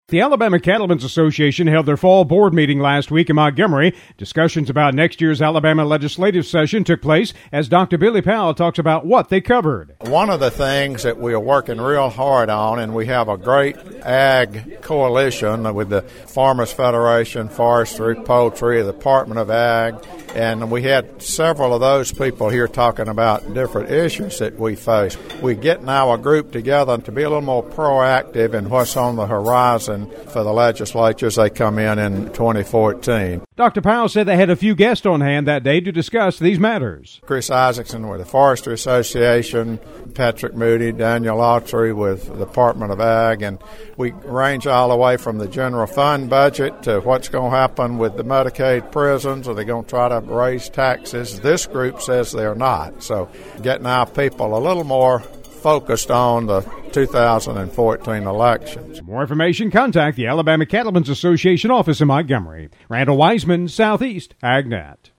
The 2014 Alabama Legislative Session was one of the topics during the recent Alabama Cattlemen’s Association fall board meeting.